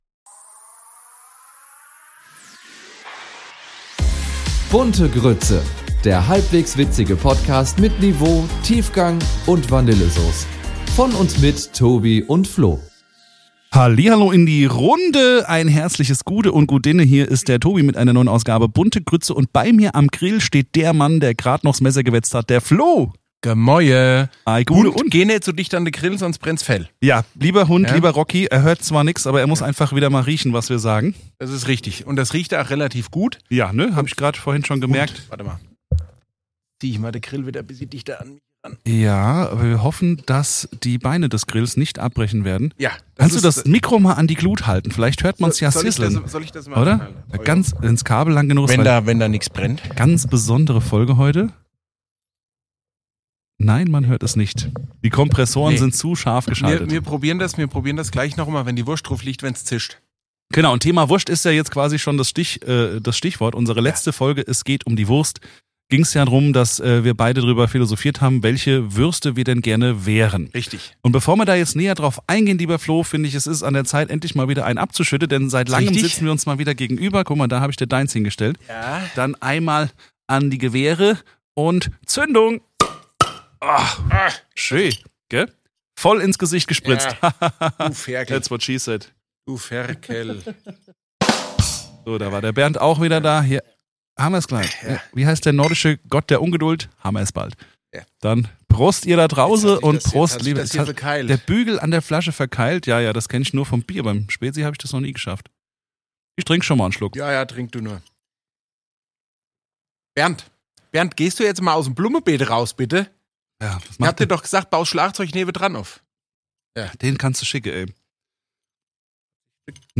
Hessisch